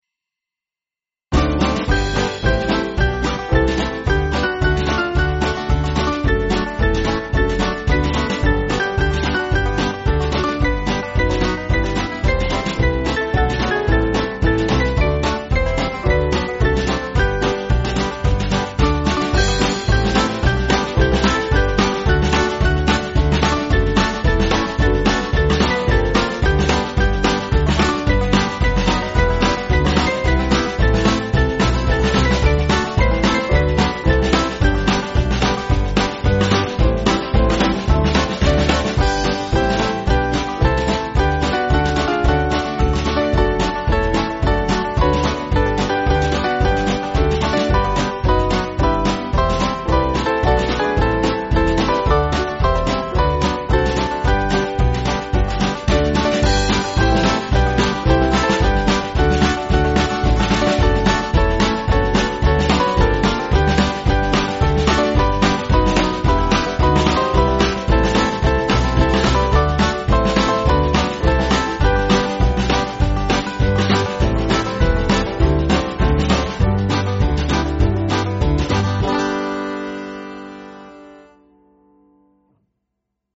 Kid`s club music
Small Band